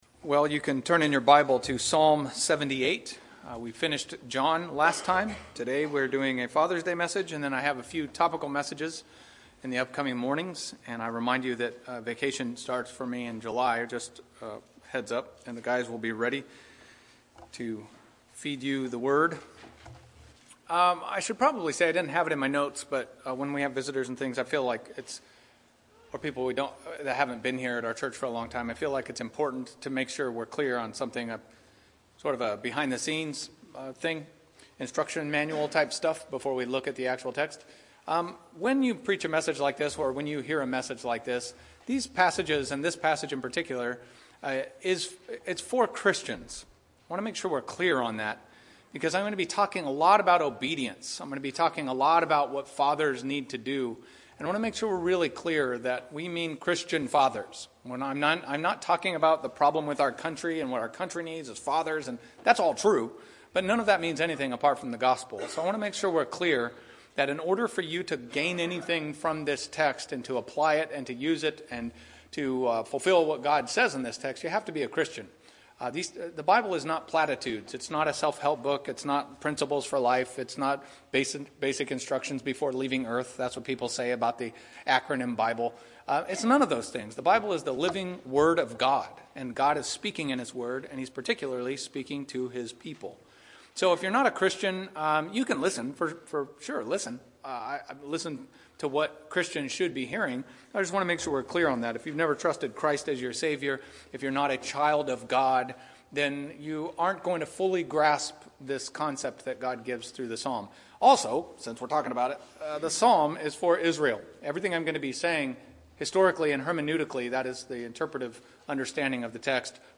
Sermons | Sovereign Grace Baptist Church
Listen to sermons and Bible-based messages from Sovereign Grace Baptist Church in Modesto, CA. Audio, video, and notes.